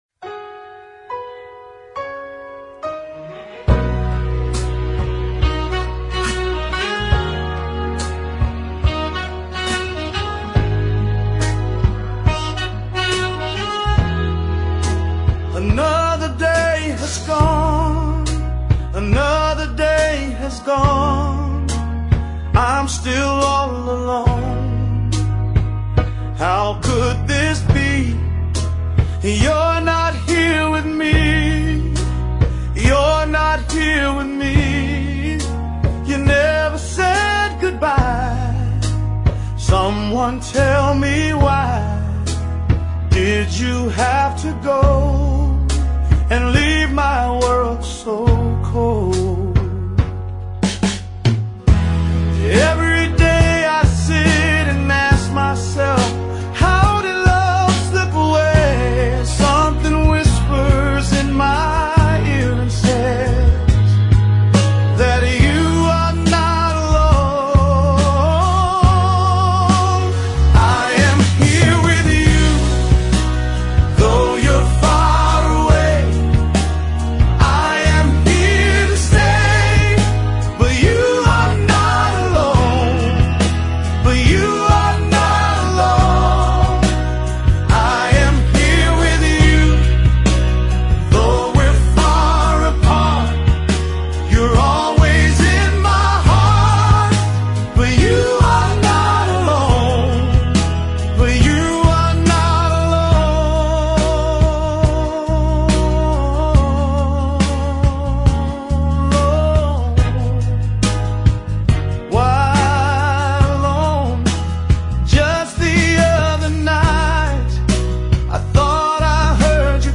Motown Soul
AI Cover